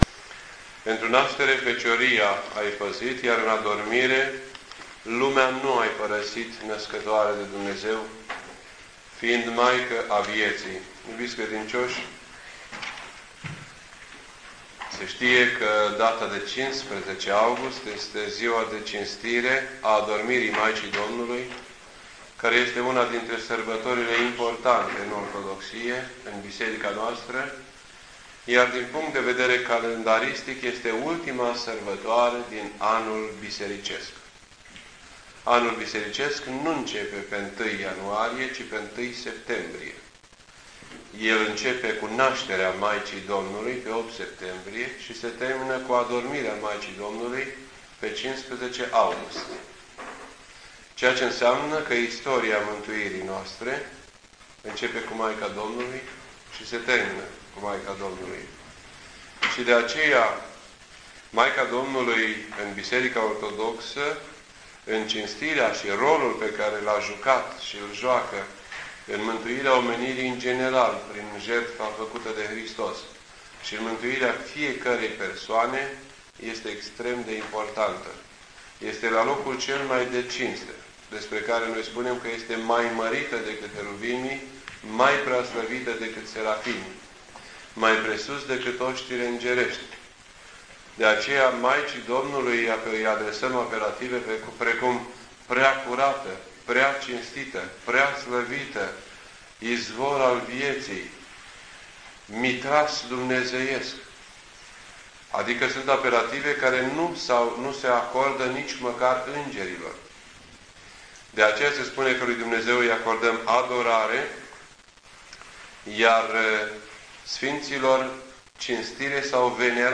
This entry was posted on Wednesday, August 15th, 2007 at 10:10 AM and is filed under Predici ortodoxe in format audio.